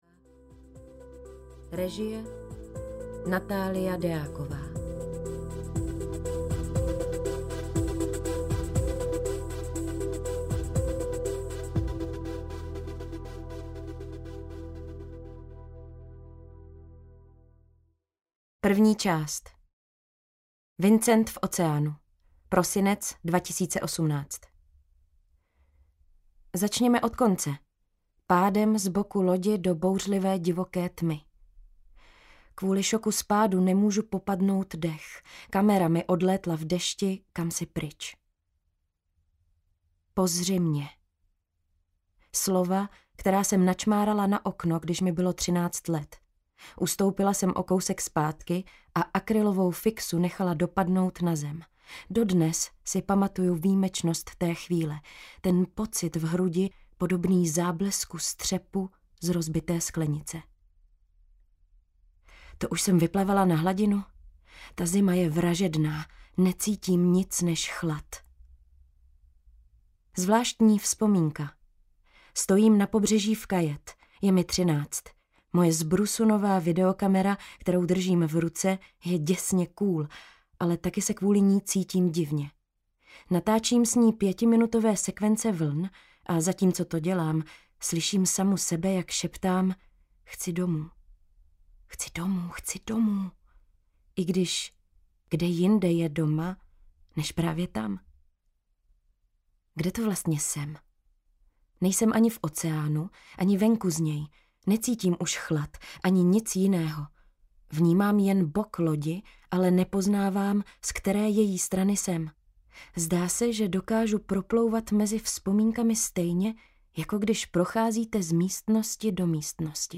Skleněný hotel audiokniha
Ukázka z knihy
skleneny-hotel-audiokniha